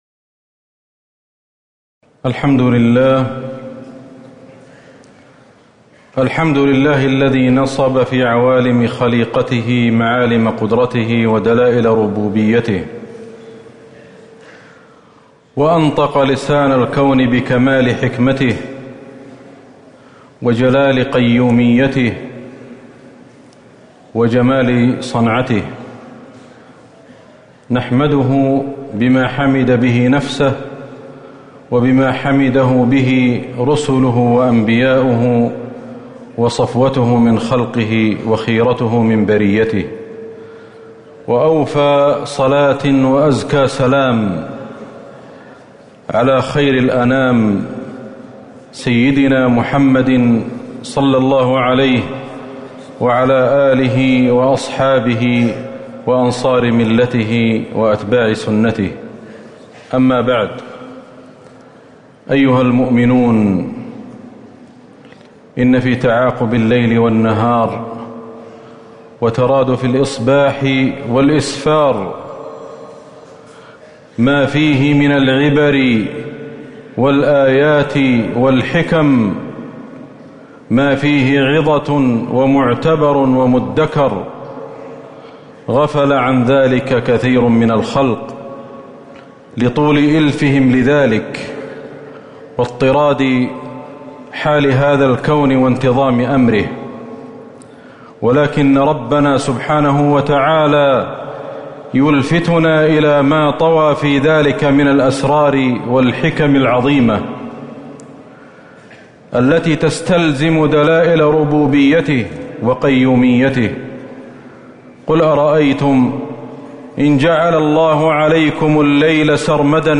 خطبة الكسوف المدينة - الشيخ أحمد الحذيفي
تاريخ النشر ٢٩ ربيع الأول ١٤٤٤ هـ المكان: المسجد النبوي الشيخ: فضيلة الشيخ أحمد بن علي الحذيفي فضيلة الشيخ أحمد بن علي الحذيفي خطبة الكسوف المدينة - الشيخ أحمد الحذيفي